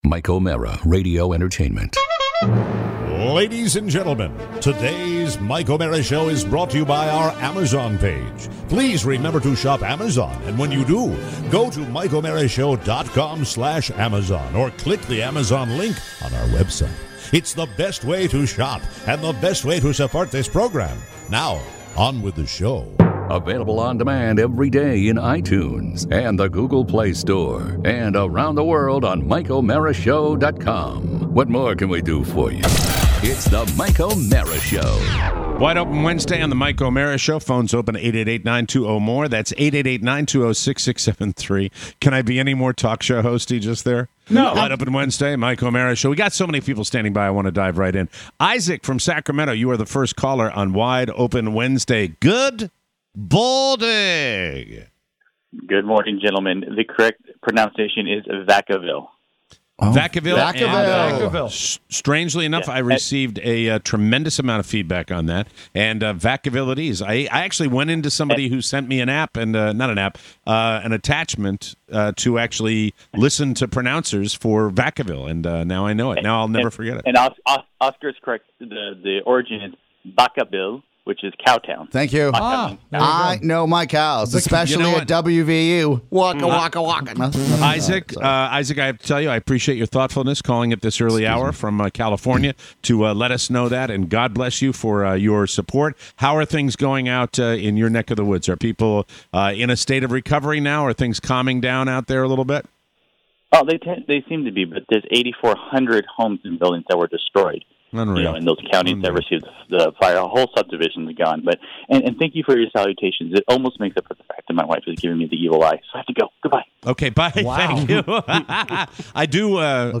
Today, your calls (weddings & wedgies)! Plus: Santas, Trunk or Treat, T-shirts… and GHWB!